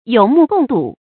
注音：ㄧㄡˇ ㄇㄨˋ ㄍㄨㄙˋ ㄉㄨˇ
有目共睹的讀法